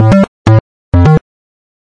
基调舞蹈A2 f2 128 bpm 2
描述：bassline dance a2 f2 128 bpm 2.wav
Tag: 最小 狂野 房屋 科技 配音步 贝斯 精神恍惚 舞蹈 俱乐部